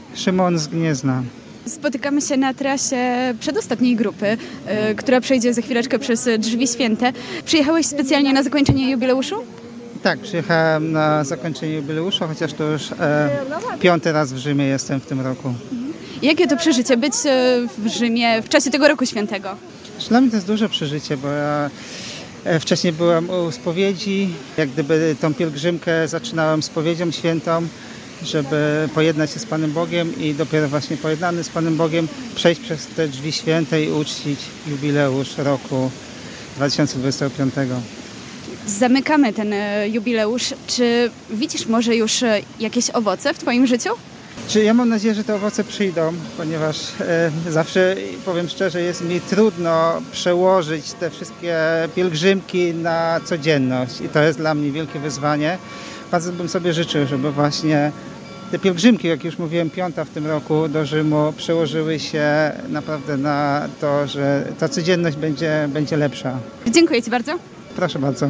Rozmowy, które podczas naszego pobytu w Watykanie przeprowadziliśmy z uczestnikami zakończenia Roku Jubileuszowego pokazują, że był to czas głębokiego duchowego doświadczenia, odnowy serca i umocnienia wiary – zarówno osobistej, jak i wspólnotowej.